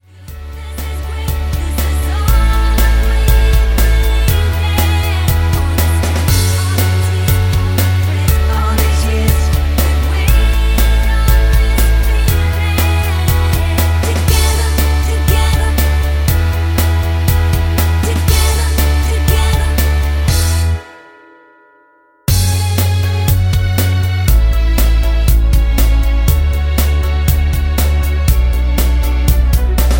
Backing track files: Duets (309)